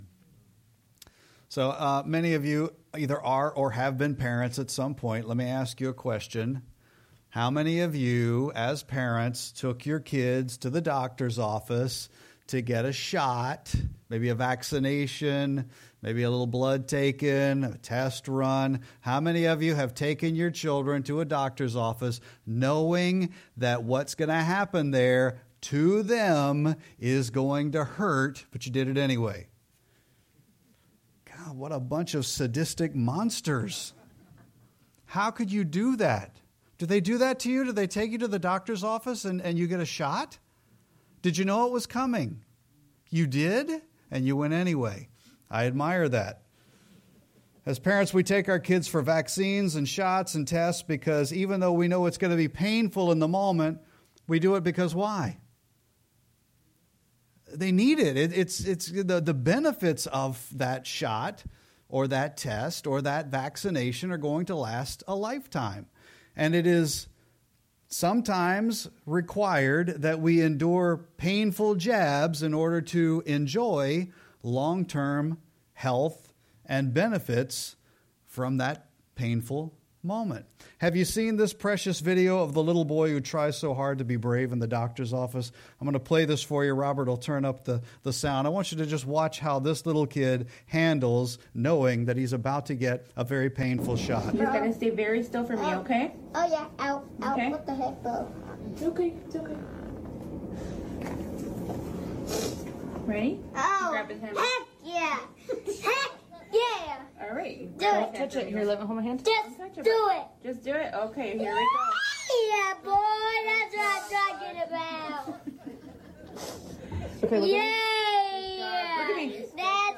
Sermon-9-28-25.mp3